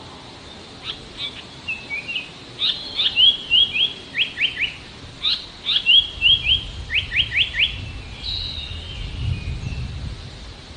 寿带鸟叫声 白带子叫声为断续的鼻音啁啾